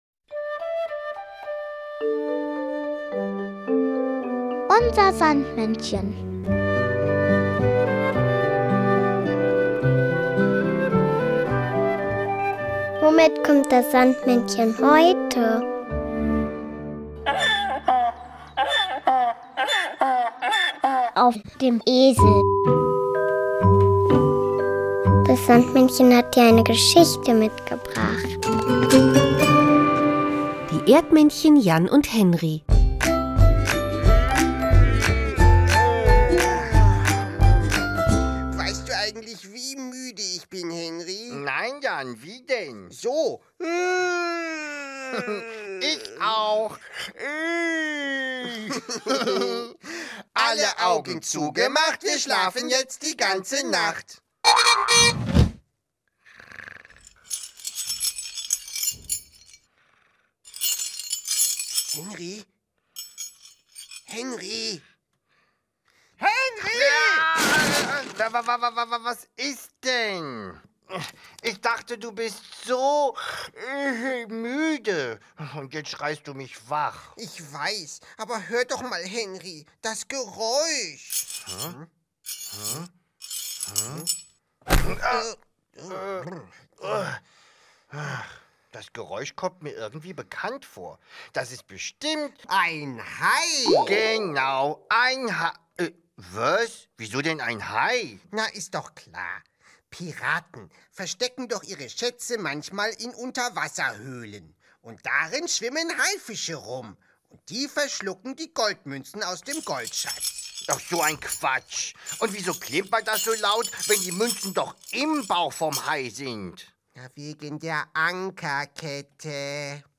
Und das Beste: Man hört, mit welchem Fahrzeug das Sandmännchen heute vorbeikommt! UNSER SANDMÄNNCHEN hat aber nicht nur zauberhafte Hörspiele dabei, sondern auch noch ein passendes Lied und den berühmten Traumsand.